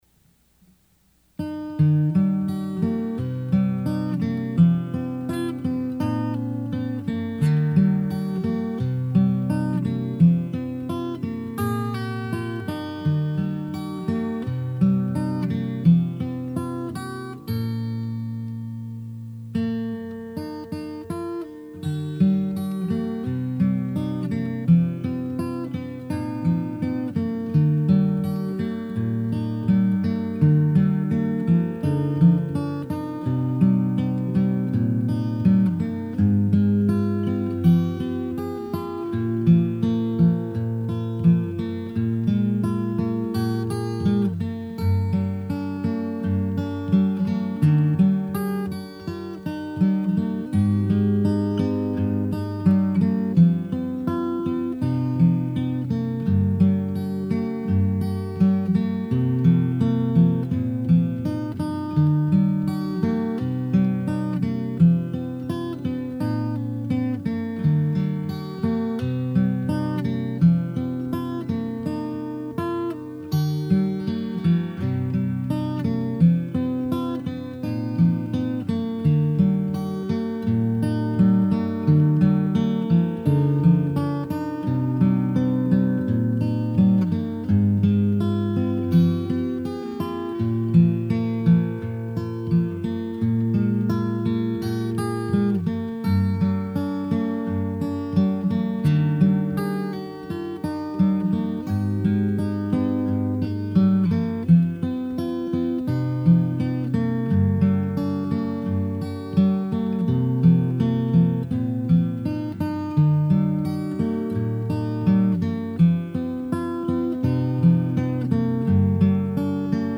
Guitar Music: “The Water Is Wide”
On August 28, 2012, I wrote a post about the classic English Folk song, “The Water Is Wide.” After giving a brief history of the song, I included a link to the recording of my instrumental fingerstyle arrangement of “The Water Is Wide.” [I played it on the guitar pictured on my home page!]